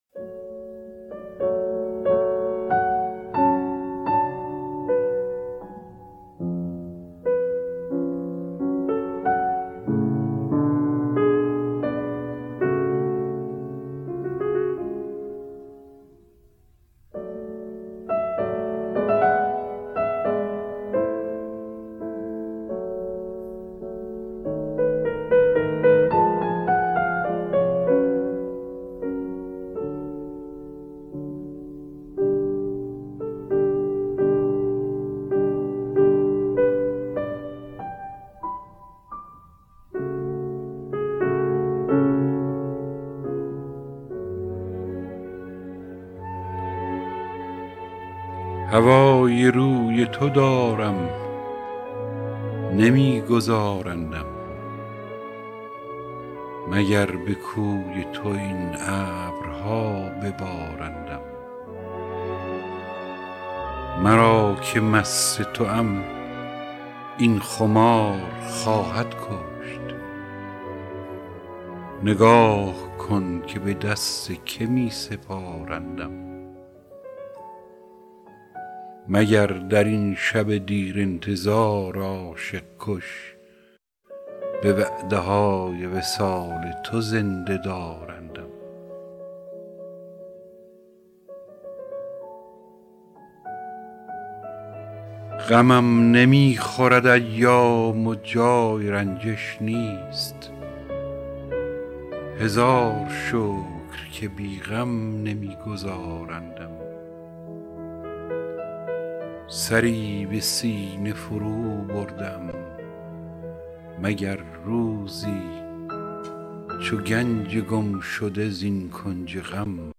دانلود دکلمه گنج گمشده با صدای هوشنگ ابتهاج با متن دکلمه
گوینده :   [هوشنگ ابتهاج]